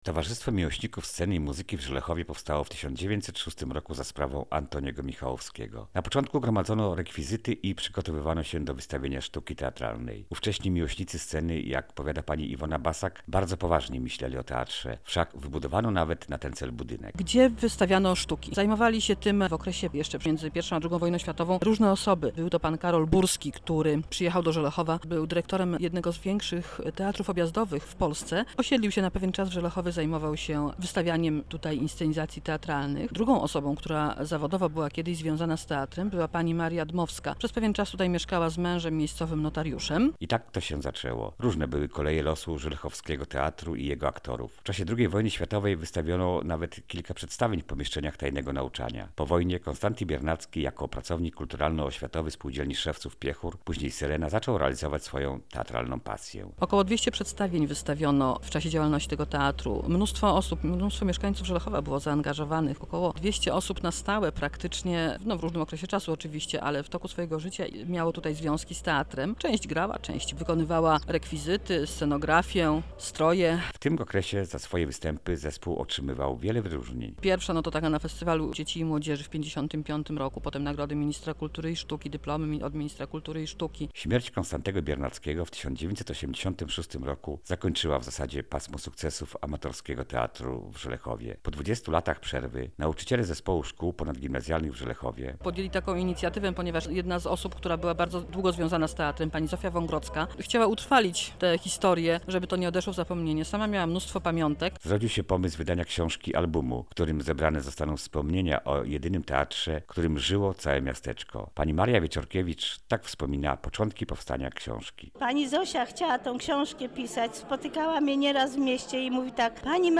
audycja_teatr.mp3